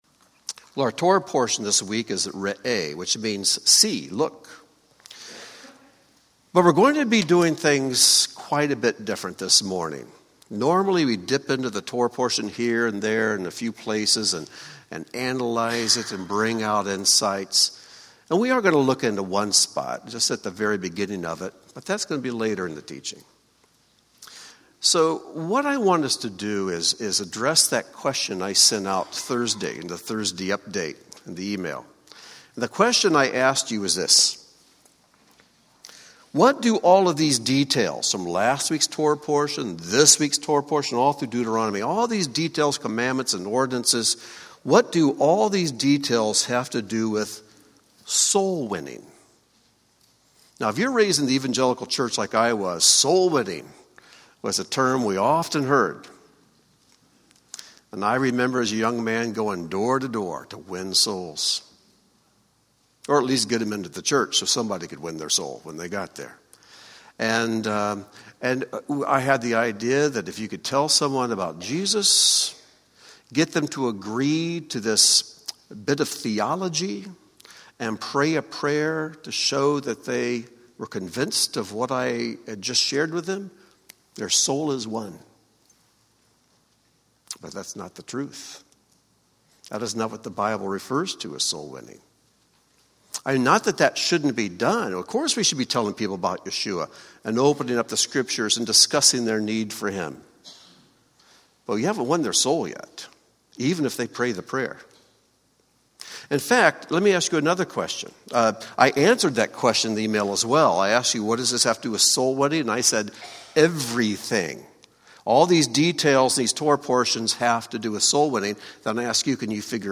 Join Beth Tikkun for this cycle's portion Re'eh teaching, a lesson focused on tweaking the traditional Christian topic of salvation of the soul.